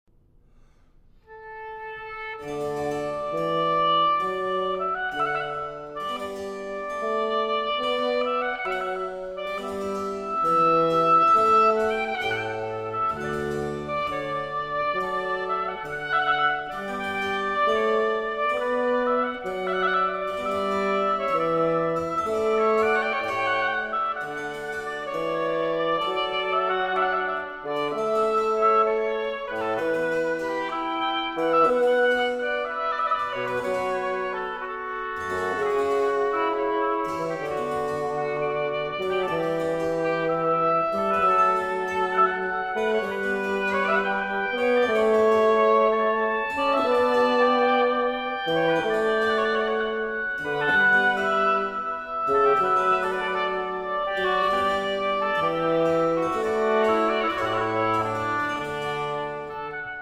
Oboe
Bassoon
Harpsichord
from Trio Sonata in D Major